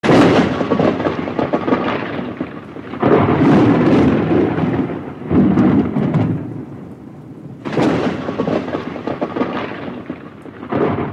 Kategori Lydeffekt